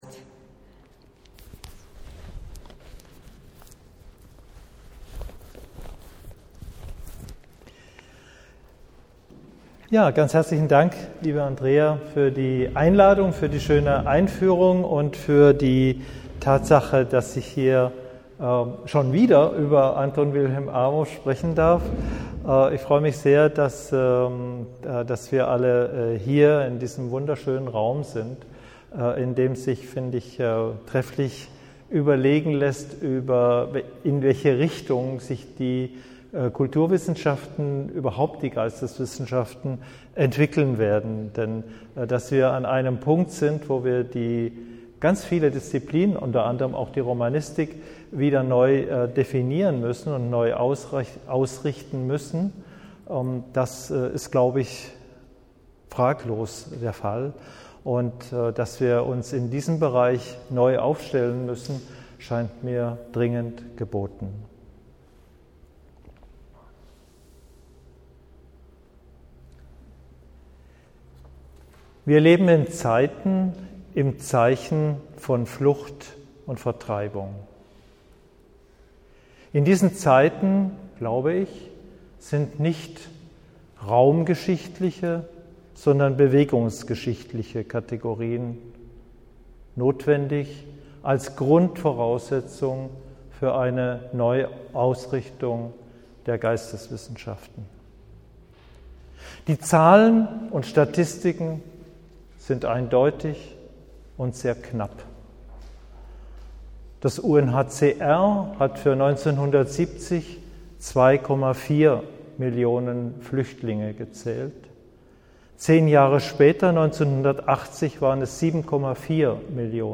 Erstes Kapitel als Hörprobe Zur Verlagsseite Interview bei rbb24 Inforadio Interview beim Deutschlandfunk Kultur Podcast zum Roman auf YouTube